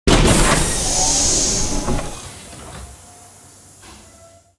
hissingdoorClose.wav